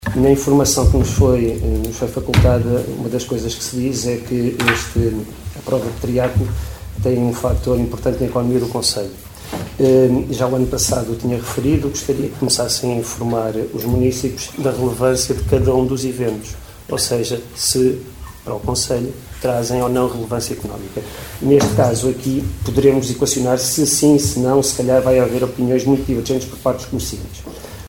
Excertos da última reunião de câmara, no passado dia 7 de Fevereiro, onde foram aprovados os apoios financeiros à Associação Triatlo de Caminha para a realização do Triatlo Longo, Meia Maratona e Corrida de São Silvestre, cujos percursos e condições se mantêm, segundo os protocolos, idênticos às edições dos anos anteriores.